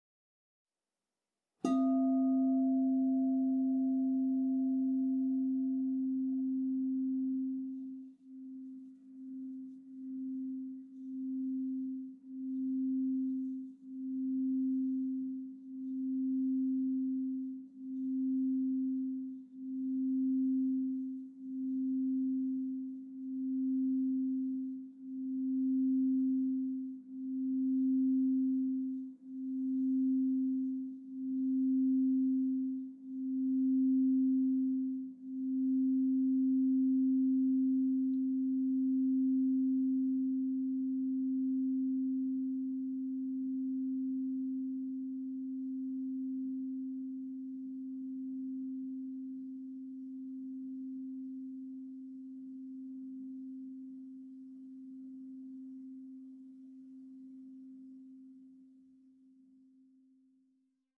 Meinl Sonic Energy 12" white-frosted Crystal Singing Bowl C4, 440 Hz, Root Chakra (CSBM12C4)
The white-frosted Meinl Sonic Energy Crystal Singing Bowls made of high-purity quartz create a very pleasant aura with their sound and design.